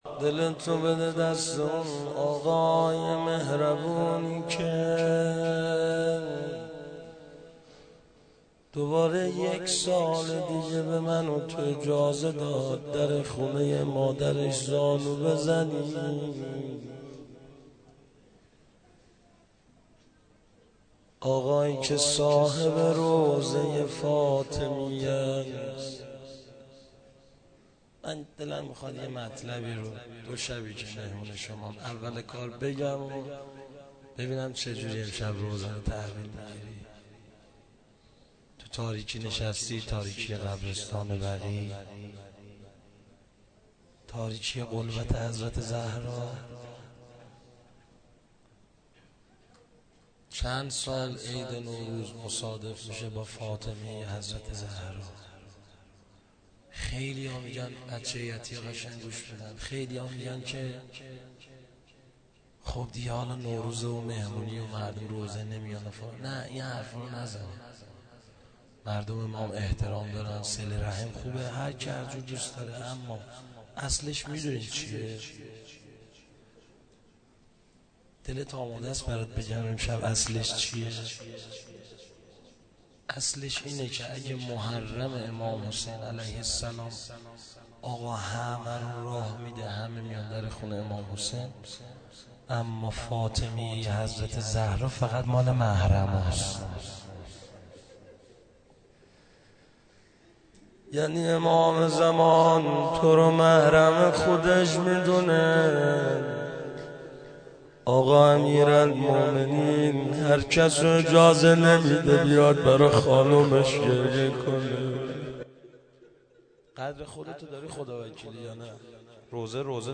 فاطمیه93هیئت امام موسی کاظم(ع)برازجان